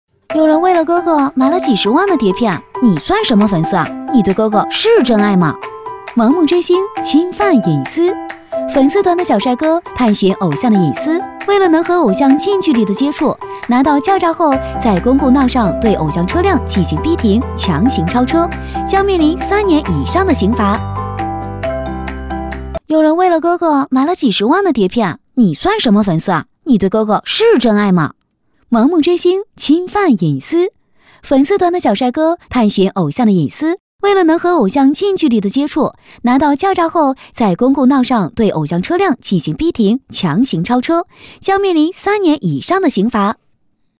飞碟说-女17-MG盲目追星.mp3